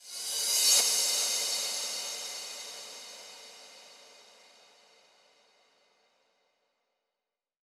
VTS1 Incast Kit Sound FX
VTS1 Incast Kit 140BPM ReCrash.wav